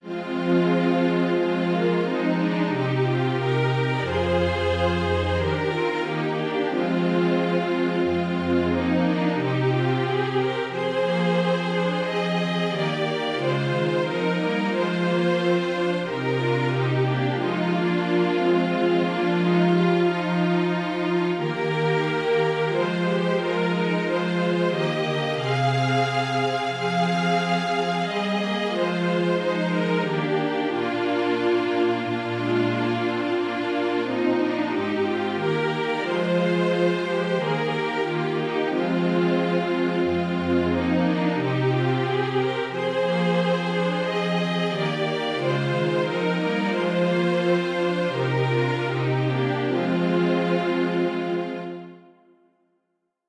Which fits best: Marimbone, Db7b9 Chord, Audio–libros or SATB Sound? SATB Sound